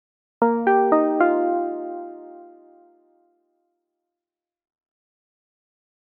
Stationslyde inkl. højttalerudkald
I forbindelse med indførelsen af nyt højttalersystem på Københavns Hovedbanegård i 2012 er meddelelseslyden blevet opdateret - og vil på sigt erstatte den gamle DSB jingle overalt i landet.
meddelelseslyd.mp3